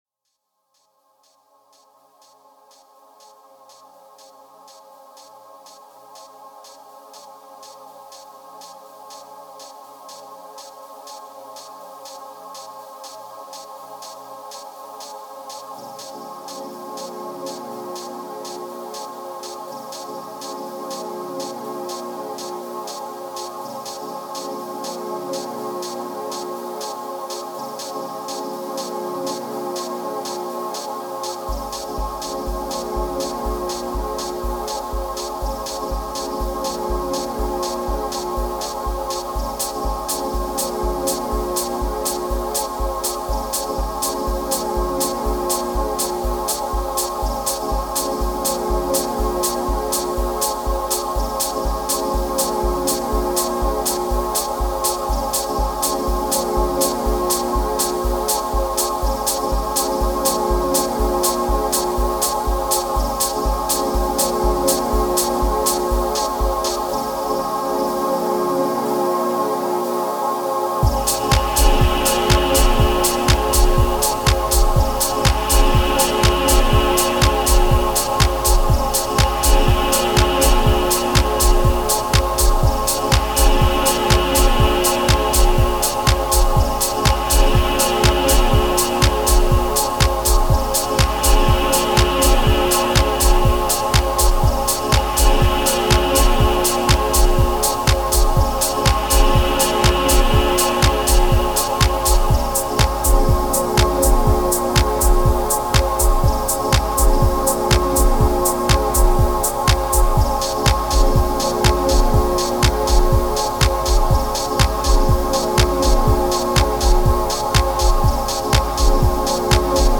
Genre: Dub Techno/Deep Techno.